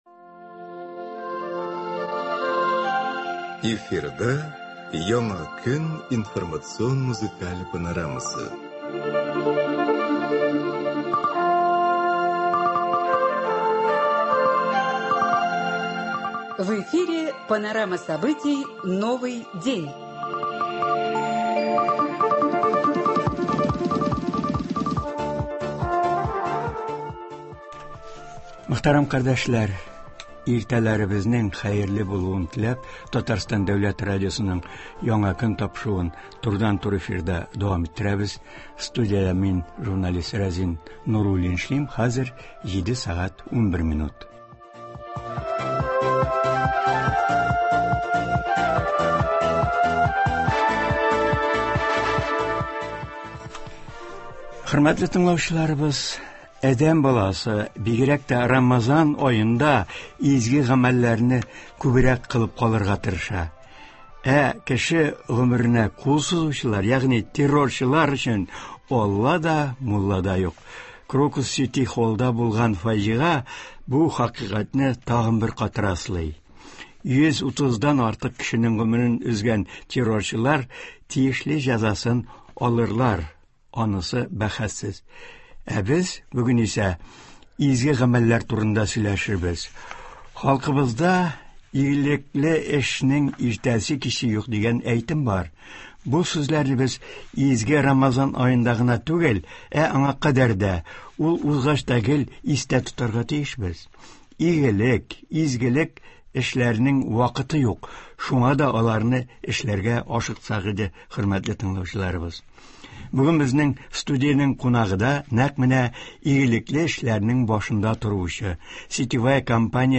турыдан-туры эфирда шулар хакында сөйли, тыңлаучылардан килгән сорауларга җавап бирә.